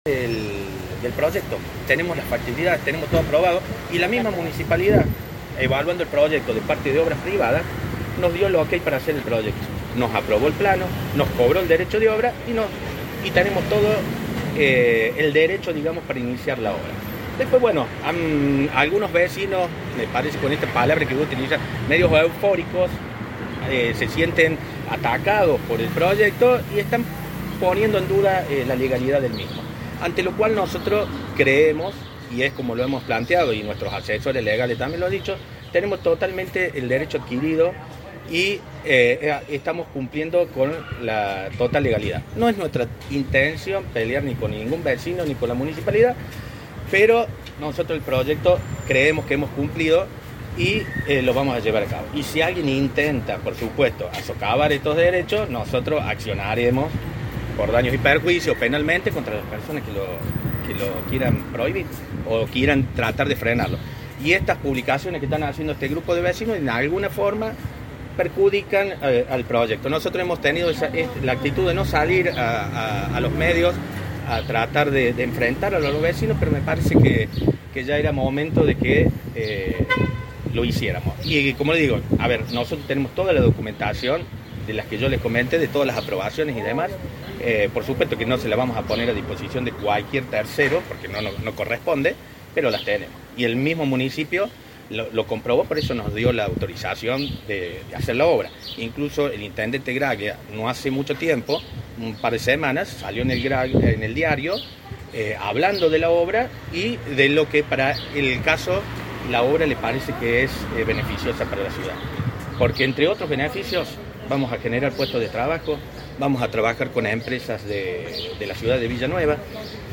Audio y video: declaraciones